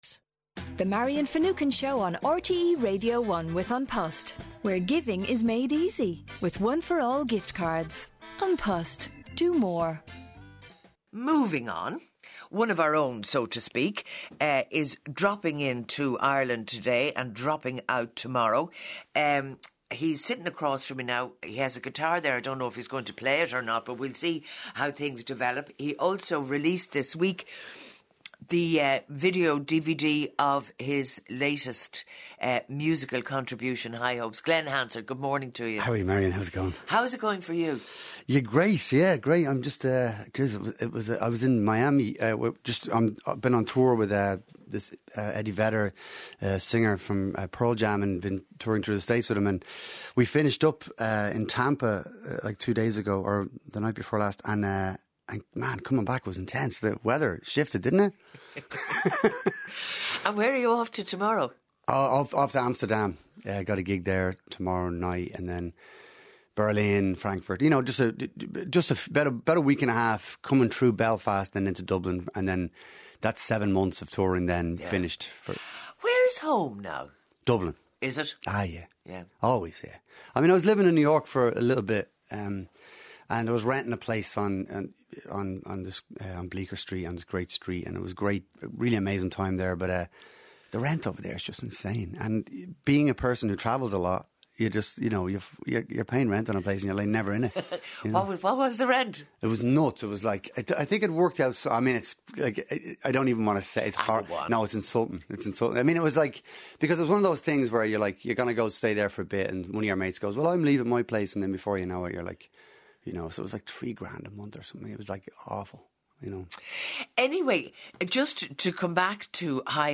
Lovely “natural” interview with Glen Hansard on Marian Finucane’s show yesterday morning. Great to see him saying how he remembers you visiting his school and what a big occasion that was.